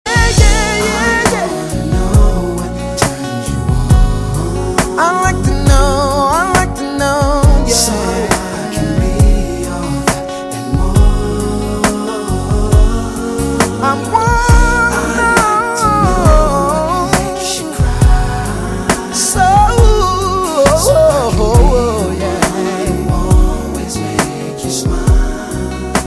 M4R铃声, MP3铃声, 欧美歌曲 111 首发日期：2018-05-13 09:36 星期日